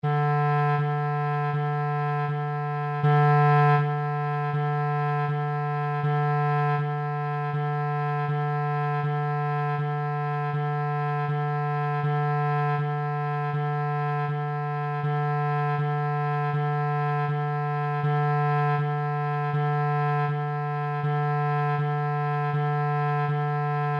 NOTAS MUSICAIS
NOTA RE